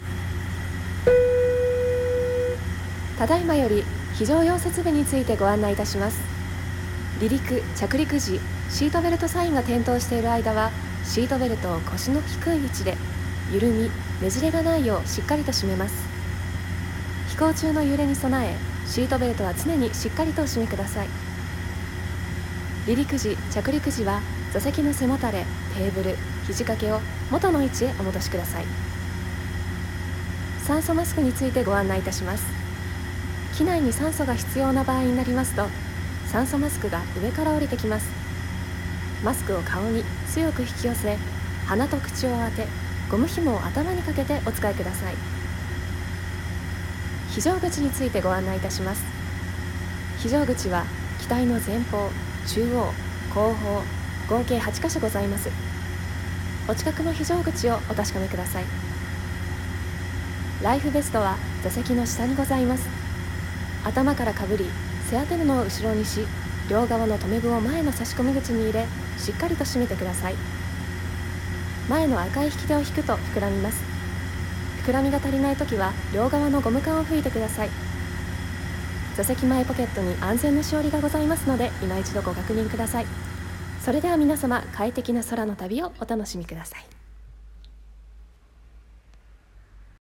機内アナウンス〜安全のご案内〜